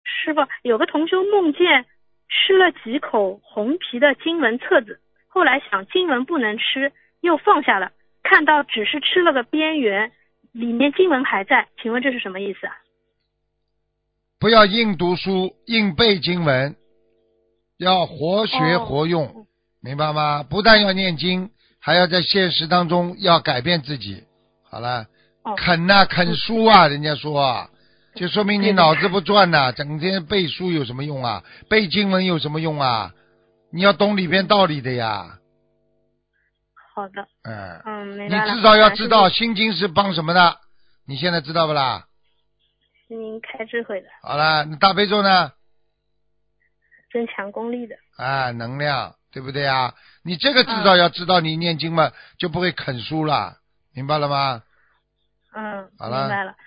▶ 语 音 朗 读